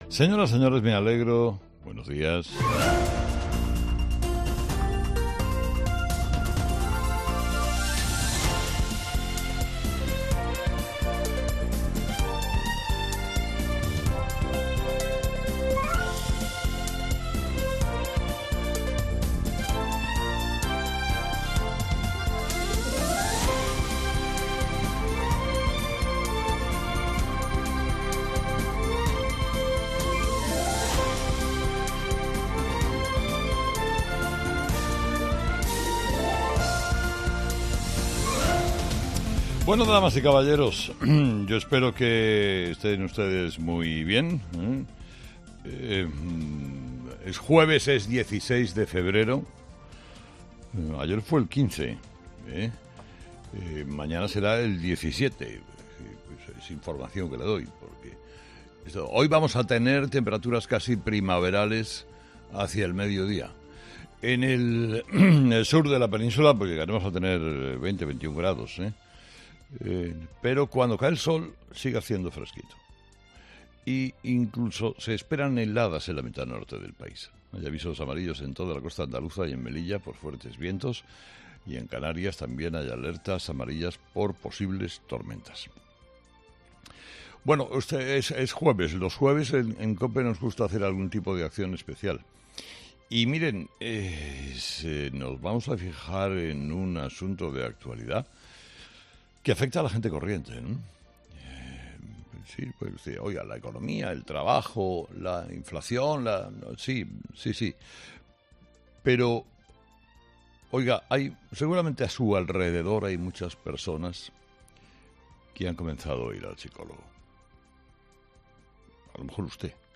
Carlos Herrera, director y presentador de 'Herrera en COPE', comienza el programa de este jueves analizando las principales claves de la jornada, que pasan, entre otros asuntos, por la aprobación de la ley trans y la del aborto en el Congreso con las consecuencias que eso va a tener.